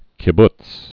(kĭ-bts, -bts)